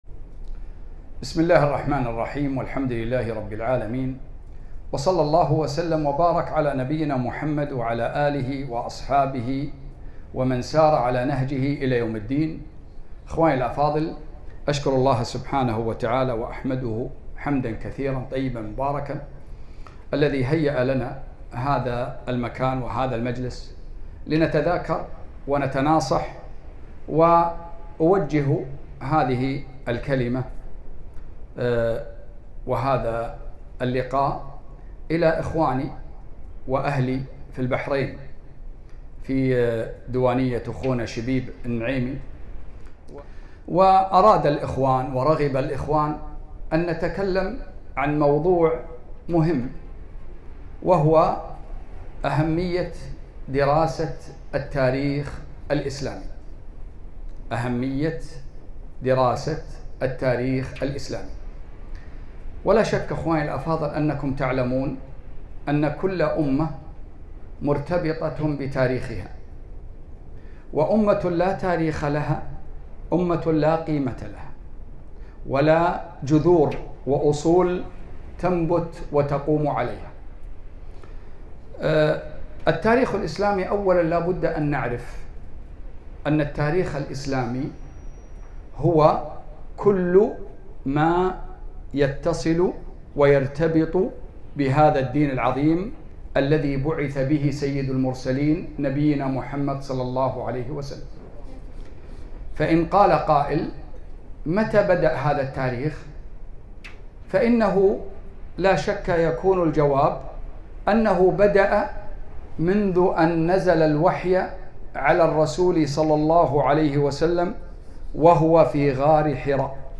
محاضرة - أهمية دراسة التاريخ الإسلامي